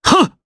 Shakmeh-Vox_Attack2_jp.wav